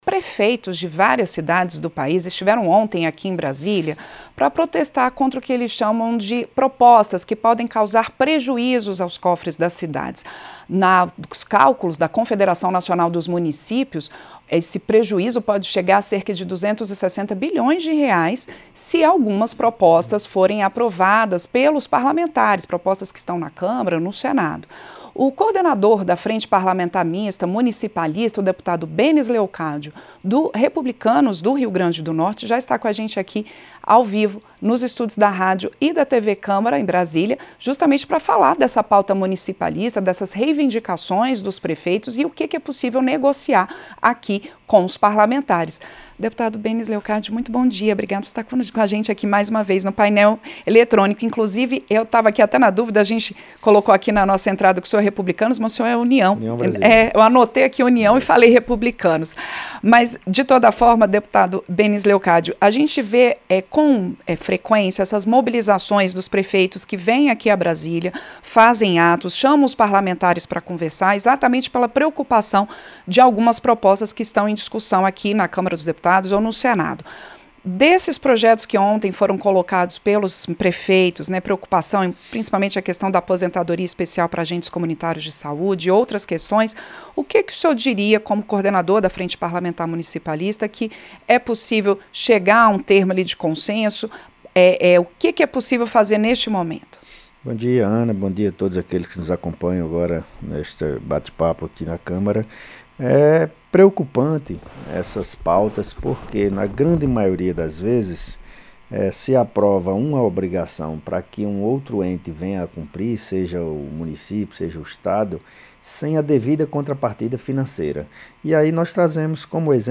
O deputado Benes Leocádio (União-RN), coordenador da Frente Parlamentar Mista Municipalista, alertou para os riscos aos caixas municipais de propostas em discussão no Congresso que criam obrigações aos gestores sem contrapartida financeira. O deputado falou ao Painel Eletrônico nesta quarta-feira (25), na manhã seguinte ao primeiro dia de mobilização de prefeitos em Brasília contra pautas em debate no Congresso.
Entrevista: Dep. Benes Leocádio (União-RN)